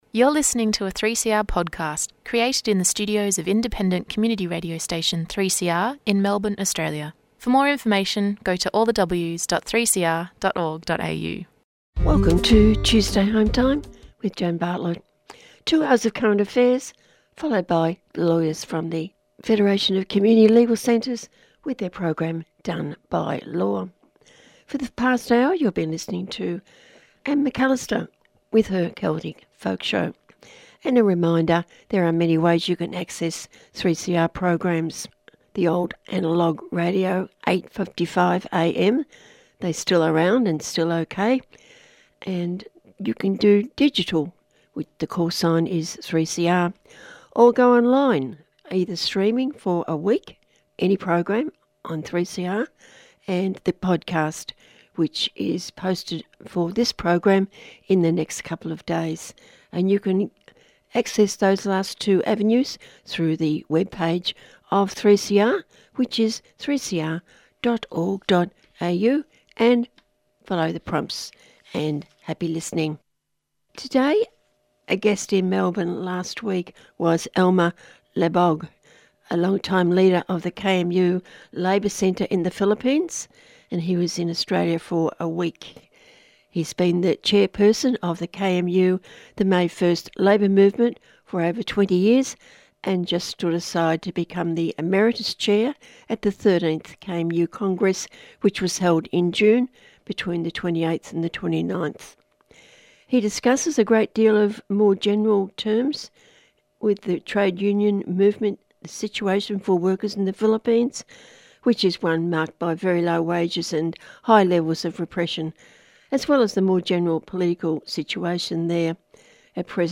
Local, national and international interviews with activists on human rights, civil liberties and animal welfare issues.